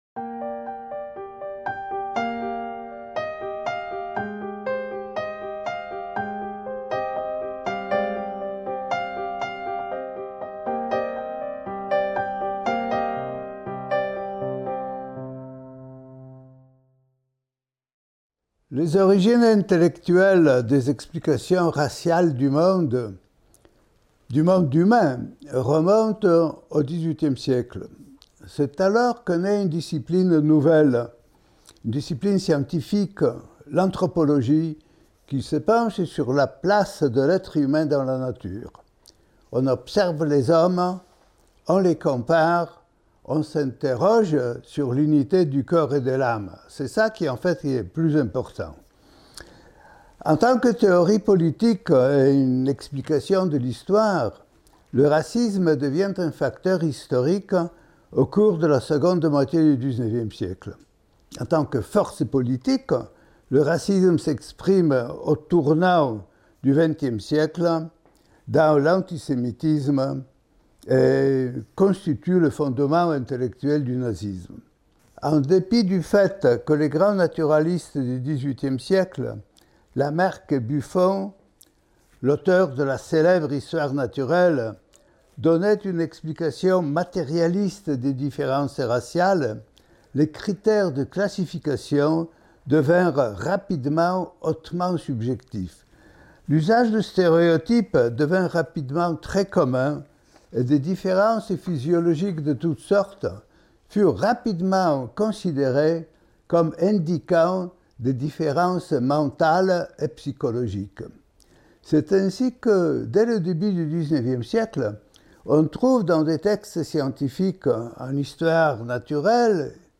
Les sources de l'idéologie raciste et antisémite - Une intervention de Zeev Sternhell | Canal U